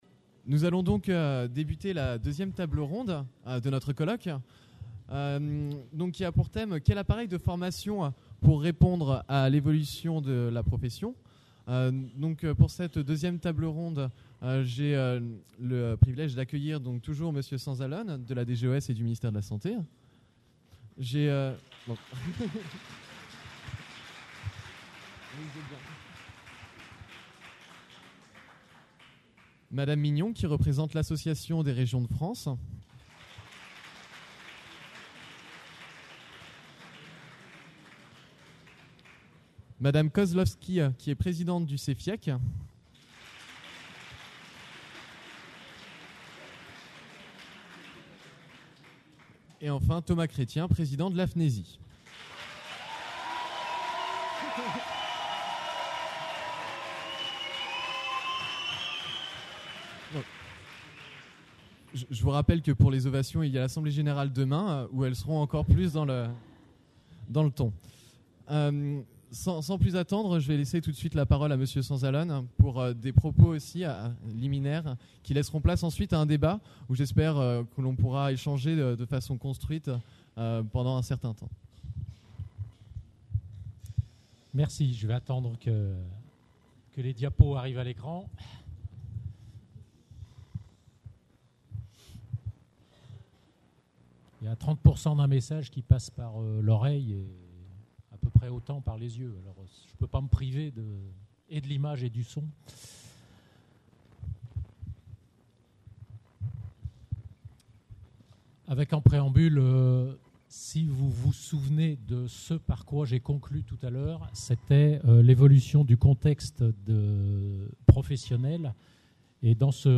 L’évolution des formations : de l’accumulation des connaissances à la mise en œuvre des compétences. Table ronde.